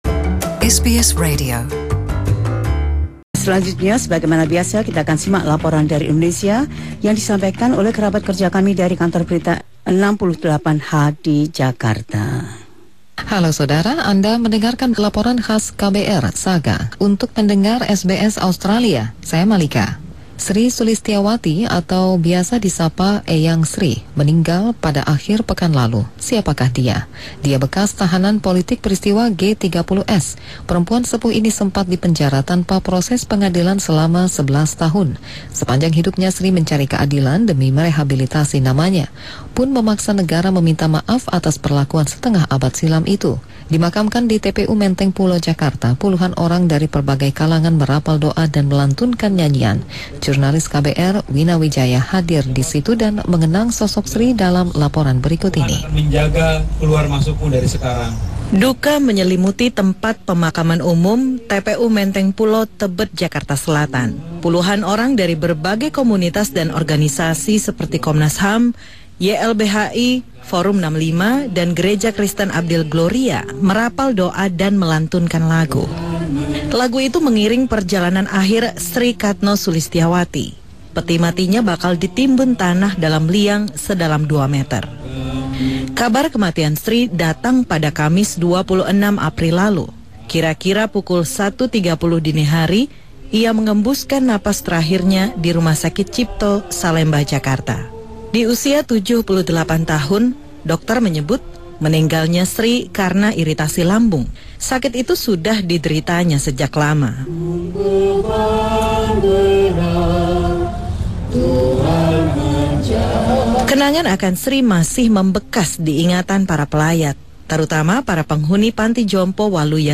Laporan khusus tim KBR 68H